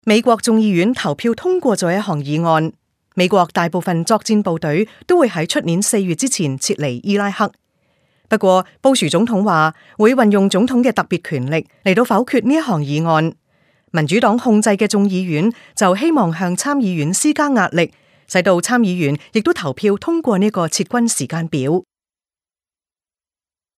Experienced broadcaster, translator and voice artist.